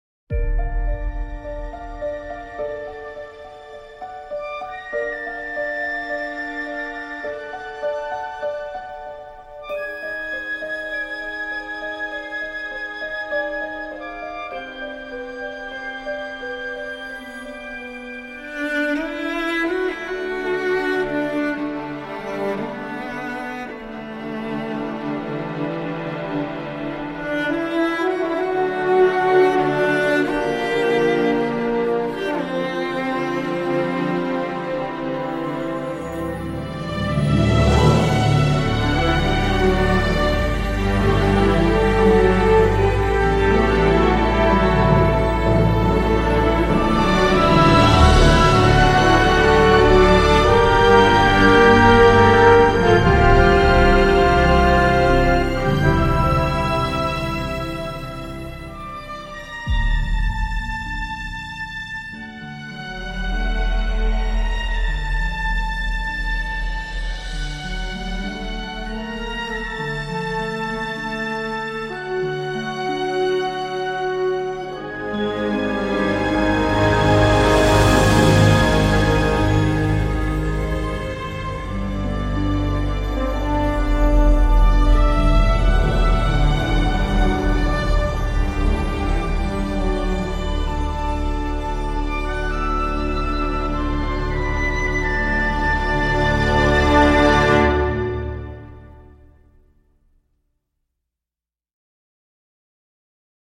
Ses morceaux plus guerriers sont finalement assez rares.